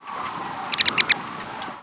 McCown's Longspur Calcarius mccownii
Flight call description A rattle call is a squeaky, popping "ku-ku-ku-dp", usually two to four notes. Other calls include an abrupt "pink" and a slightly burry "jik".
Fig.1. Arizona January 25, 2001 (WRE).
Rattle call from bird in flight.